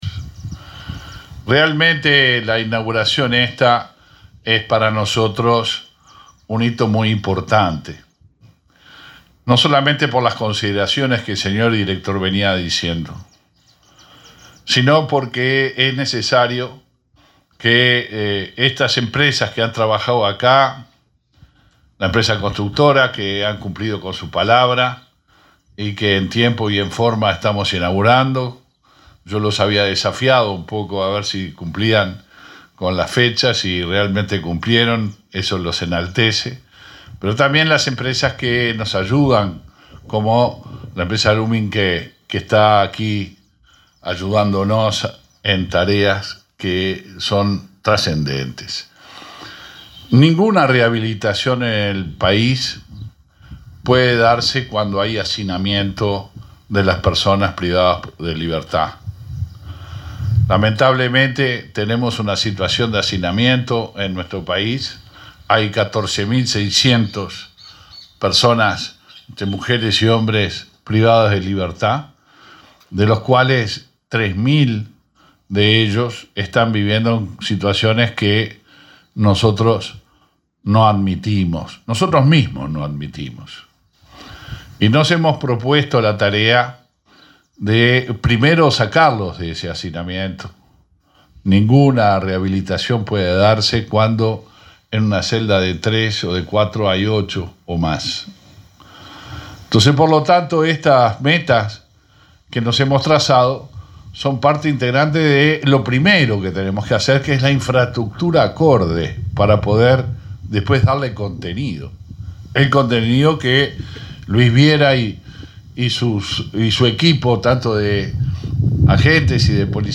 Palabras del ministro del Interior, Luis Alberto Heber
El ministro del Interior, Luis Alberto Heber, participó este jueves 15, en la inauguración de la cárcel de Tacuarembó.